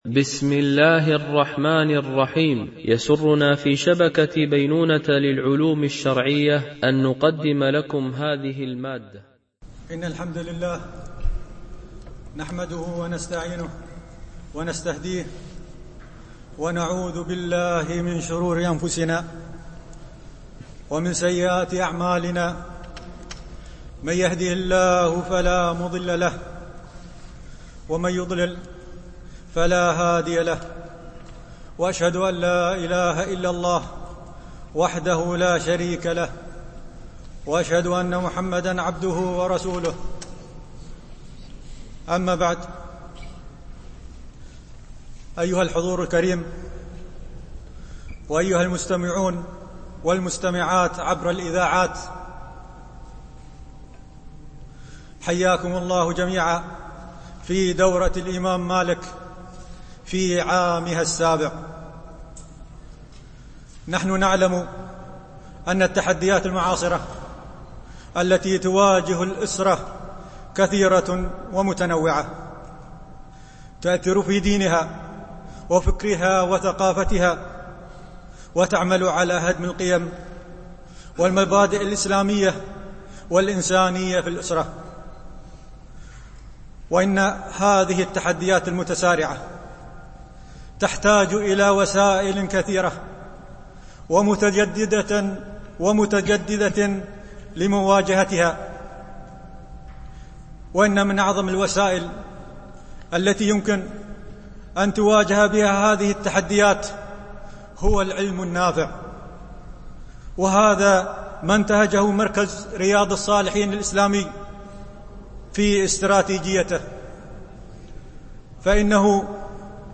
الندوة الافتتاحية: التحديات الأسرية المعاصرة